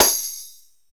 Index of /90_sSampleCDs/Roland L-CD701/PRC_Latin 2/PRC_Tambourines
PRC TAMBHIT1.wav